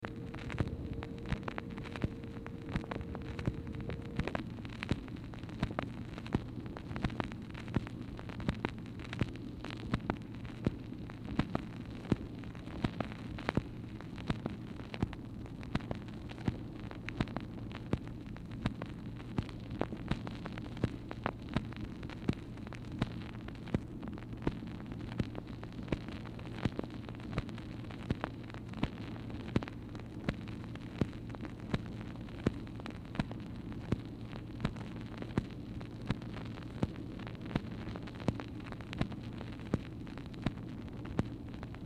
Telephone conversation # 2475, sound recording, MACHINE NOISE, 3/11/1964, time unknown | Discover LBJ
Format Dictation belt